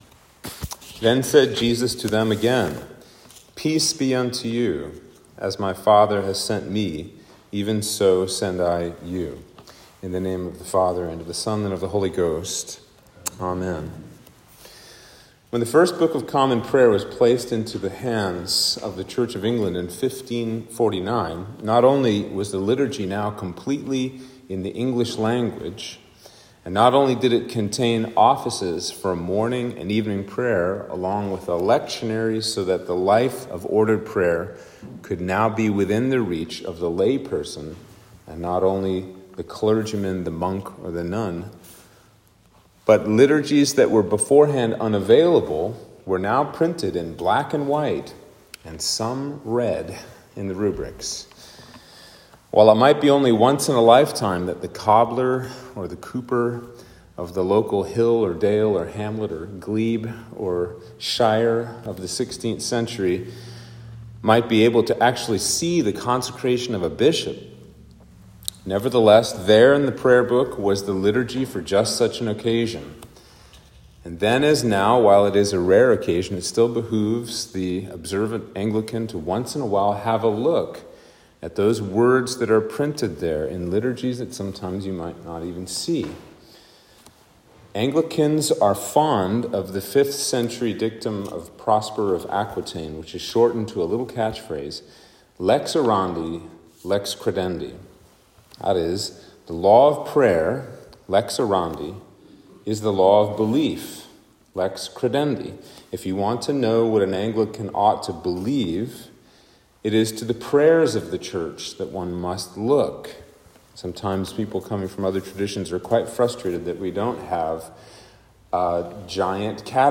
Sermon for Easter 1